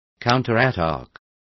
Complete with pronunciation of the translation of counterattack.